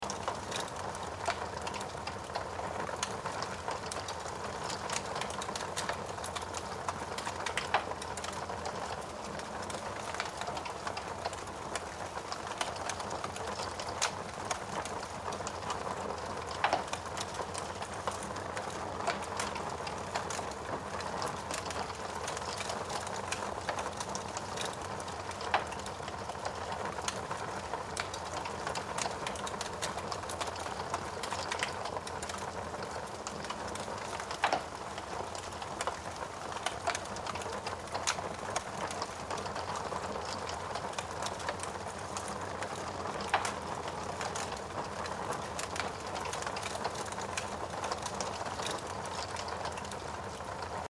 دانلود صدای طبیعت و پرندگان